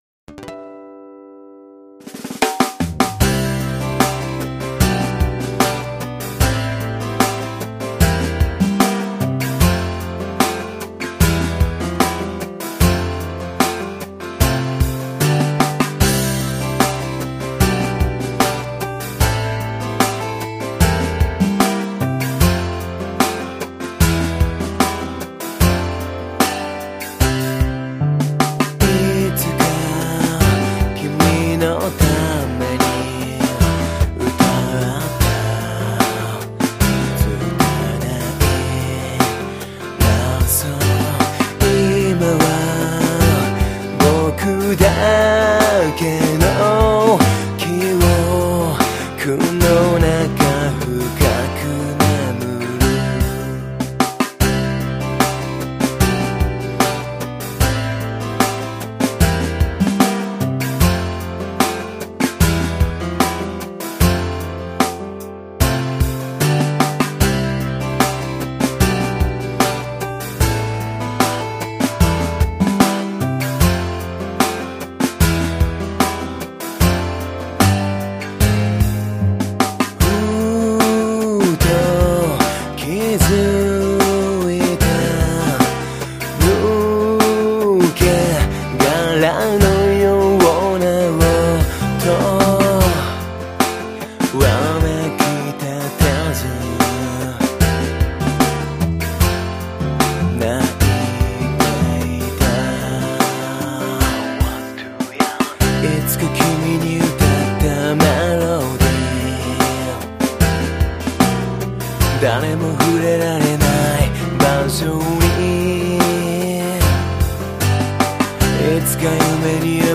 なんだか最後まで作らずにバンドやらなくなって、おそらく一番最後に作ったバンド時代のデモ曲です。
ちなみに今はもっと歌唱力落ちています。